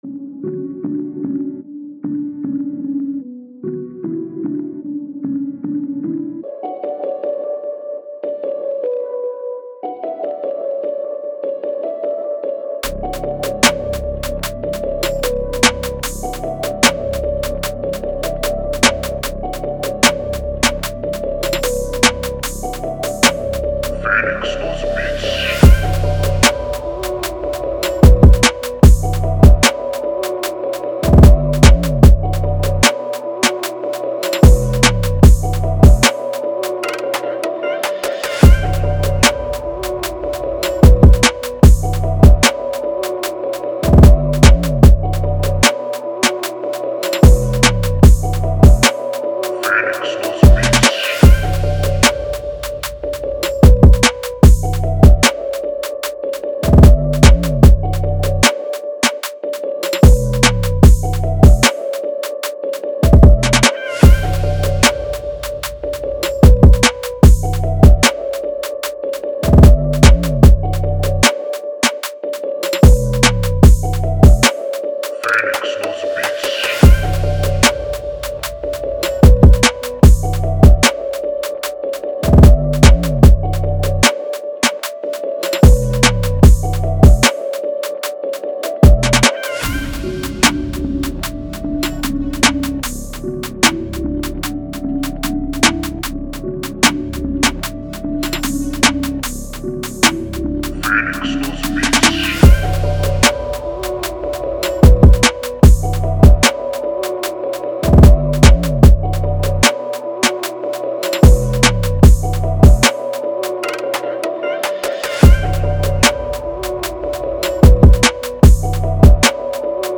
Dark Drill Instrumental